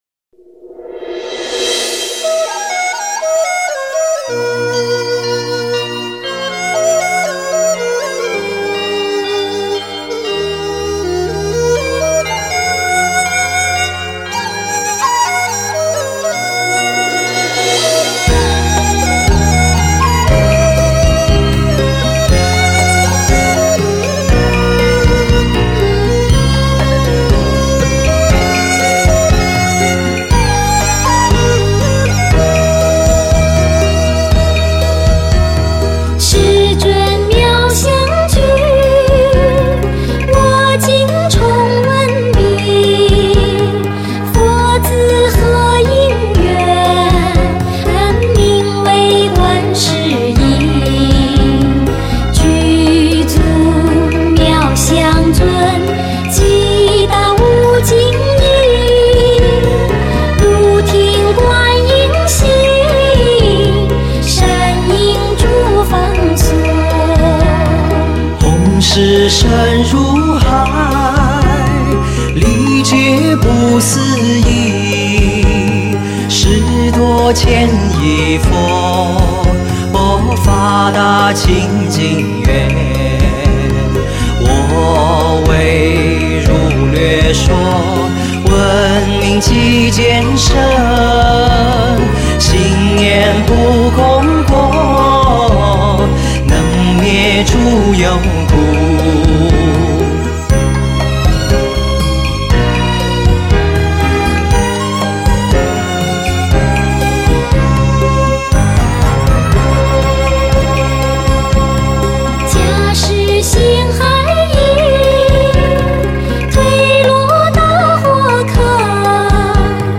[2/3/2010]法喜充满，妙韵悠扬 - 男女合唱：观音普门品